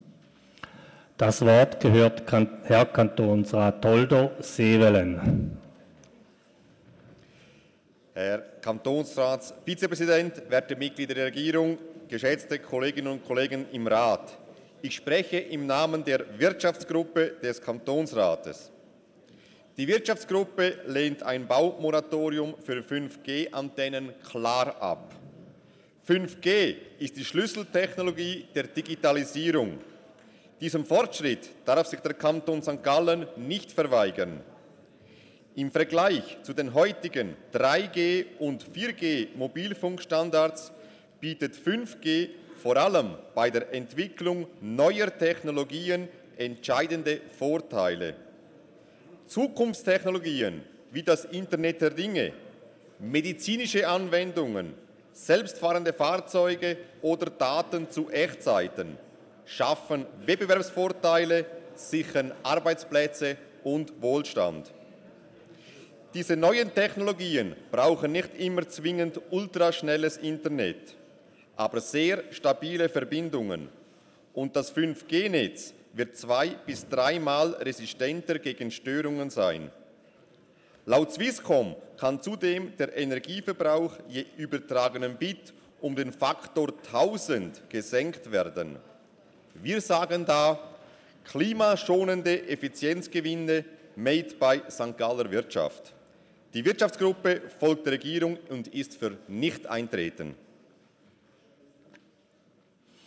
12.6.2019Wortmeldung
Session des Kantonsrates vom 11. bis 13. Juni 2019